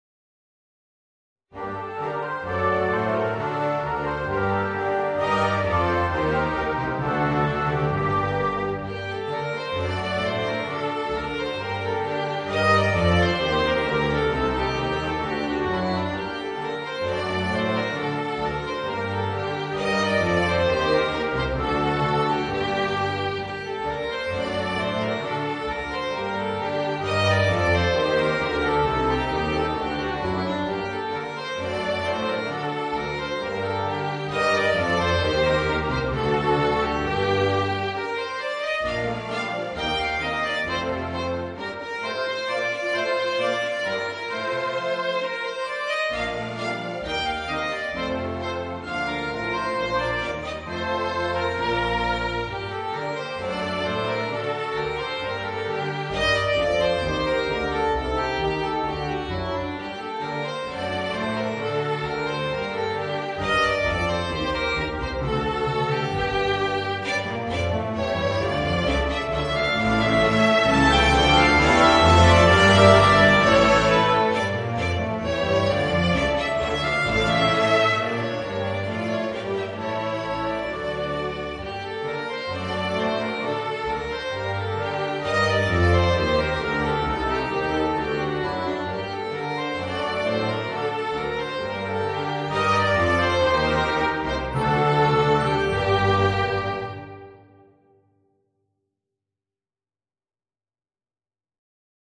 Voicing: Clarinet and Orchestra